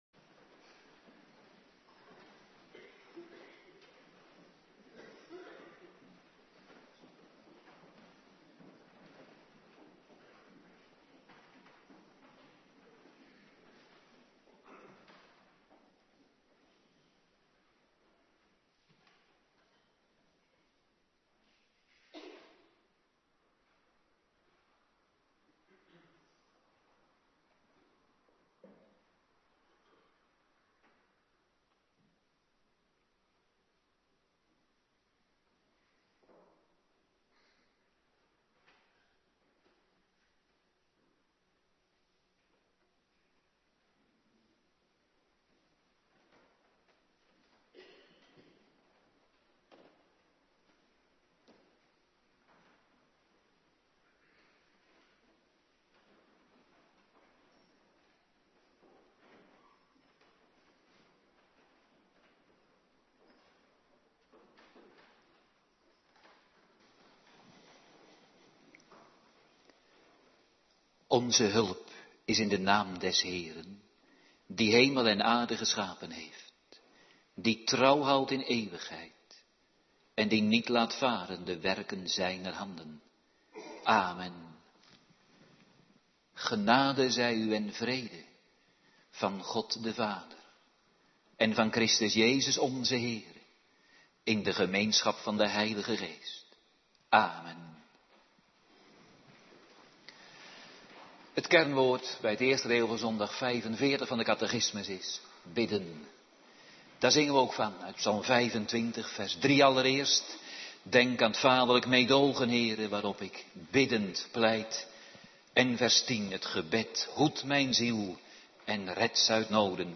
Preken (tekstversie) - Geschriften - HC zondag 45 vraag en antwoord 116 | Hervormd Waarder